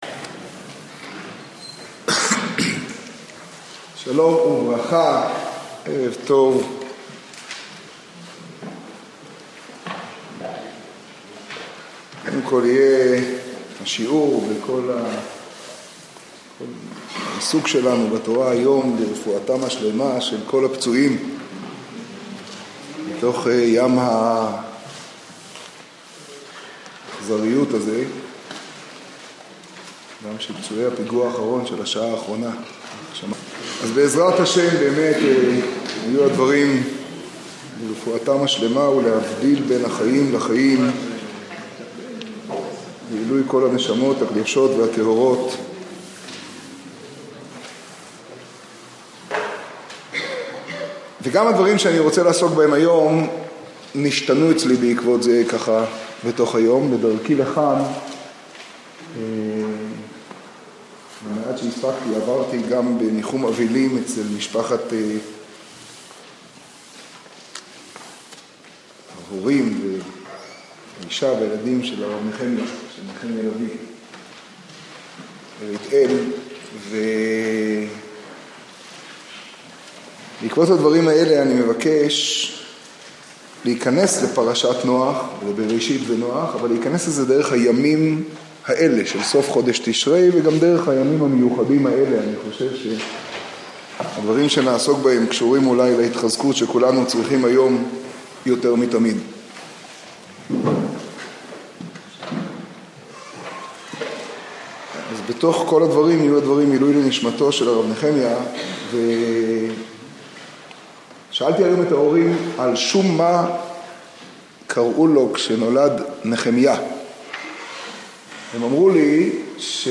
שיעורי
השיעור בירושלים, פרשת חיי שרה תשעו.